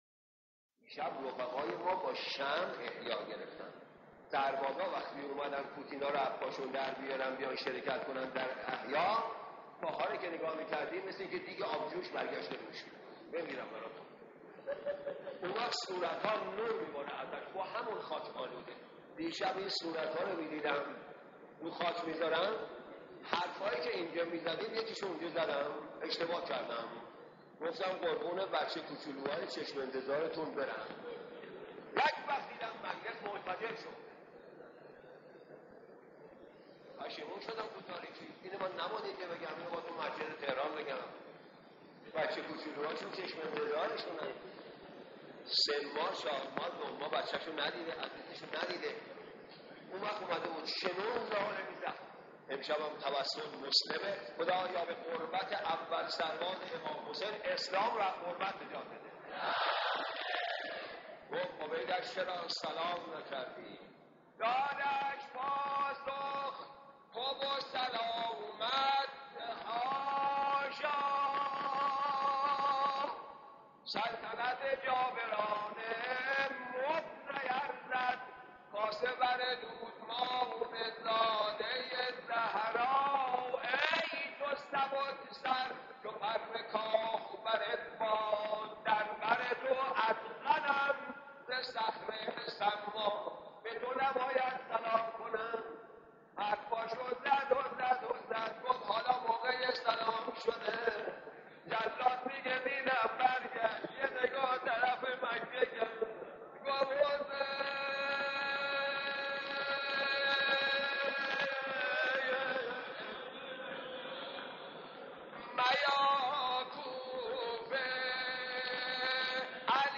صوت | نغمه‌های عاشورایی از مداحان قدیم تهران
گریز از نقل خاطره شبی از شب‌های احیای رمضان و مرثیه‌خوانی از زبان حضرت مسلم (ع)